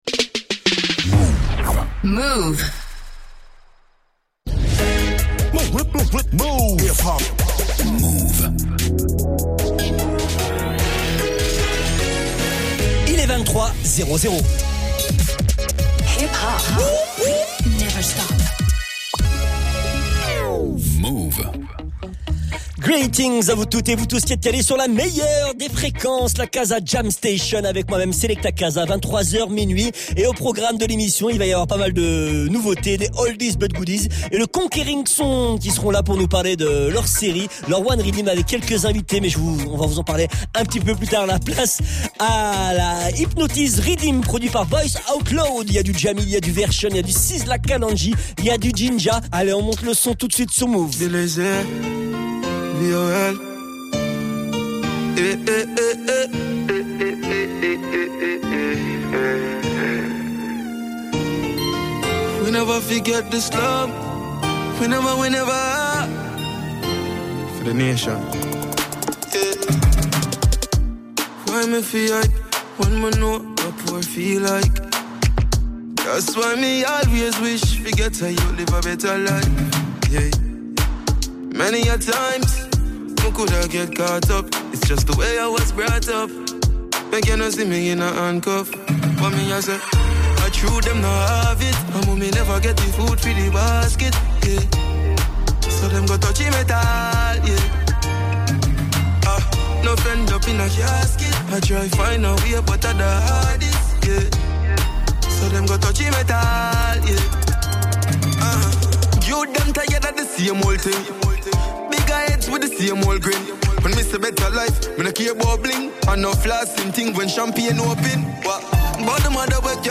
La K-Za Jam Station spécial Dancehall Reggae